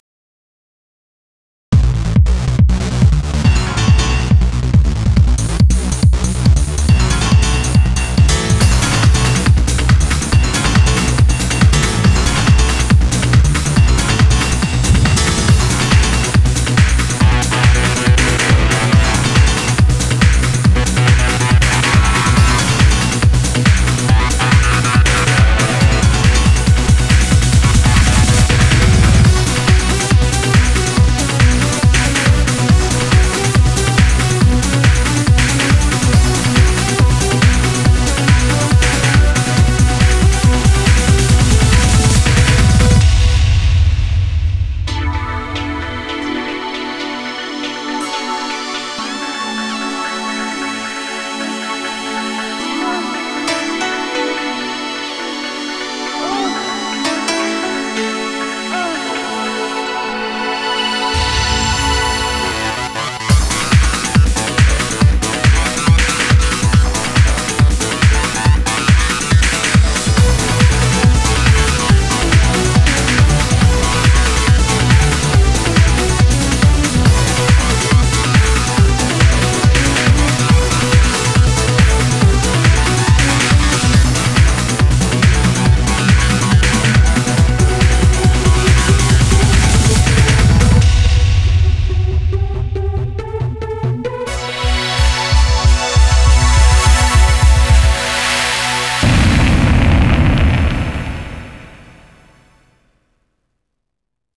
BPM111-225
Audio QualityMusic Cut